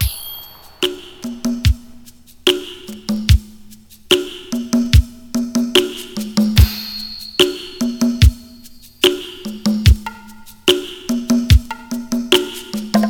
• 73 Bpm Drum Loop G# Key.wav
Free drum loop - kick tuned to the G# note. Loudest frequency: 1557Hz
73-bpm-drum-loop-g-sharp-key-HRf.wav